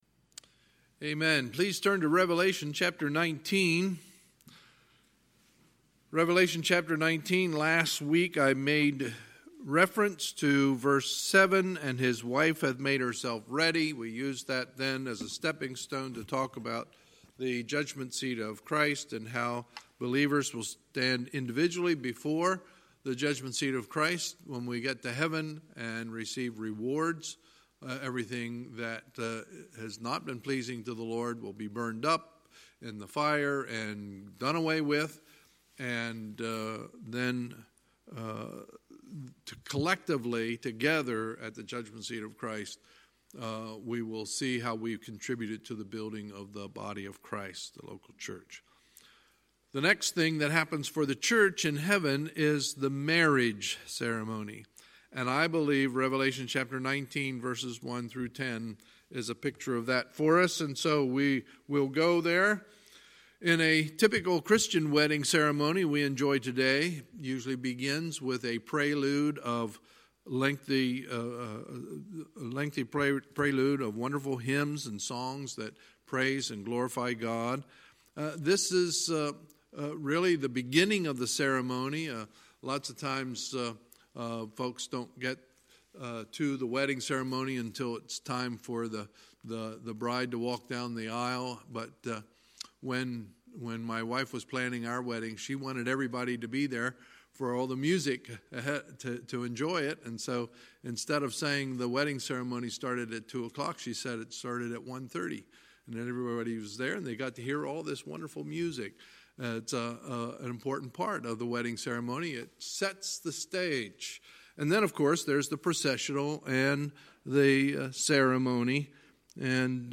Sunday, July 7, 2019 – Sunday Evening Service
Sermons